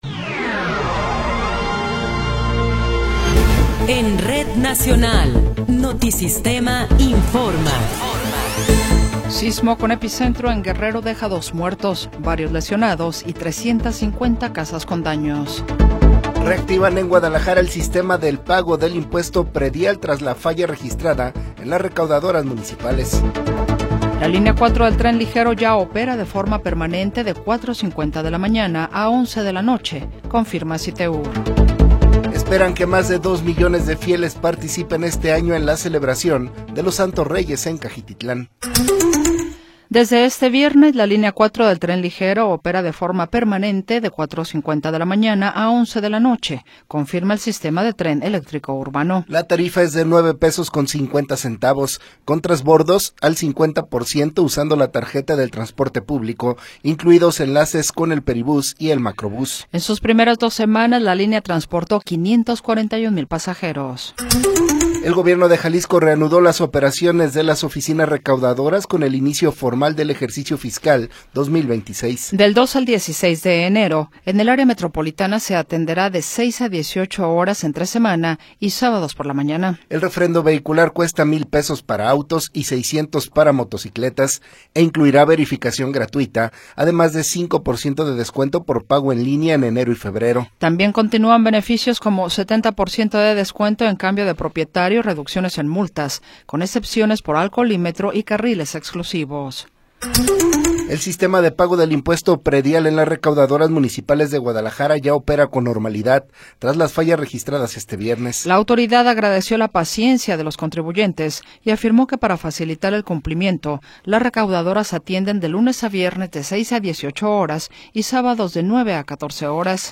Noticiero 20 hrs. – 2 de Enero de 2026
Resumen informativo Notisistema, la mejor y más completa información cada hora en la hora.